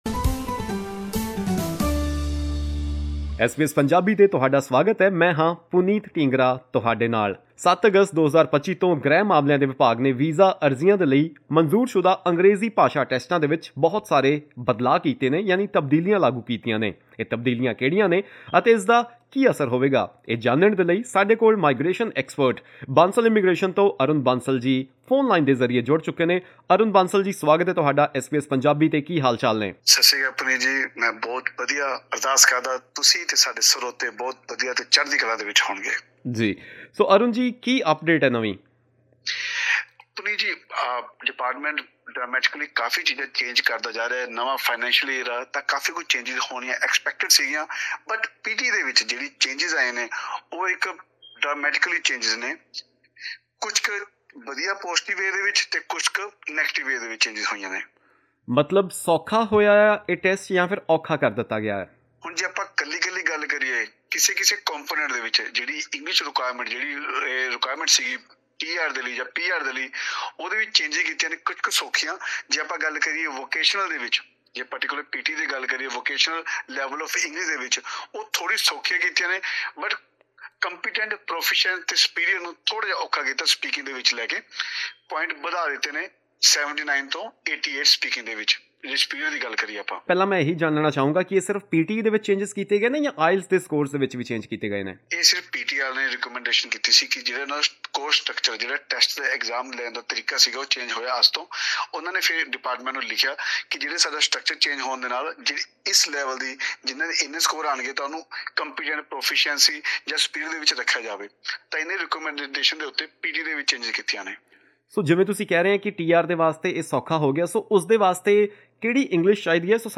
We spoke with migration expert